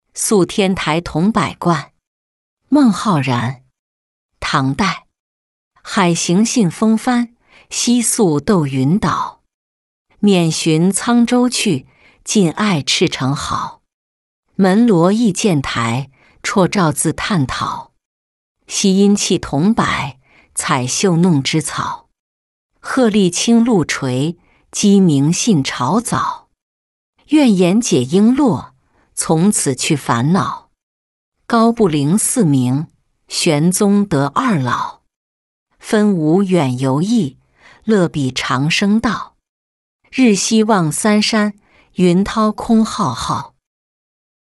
宿天台桐柏观-音频朗读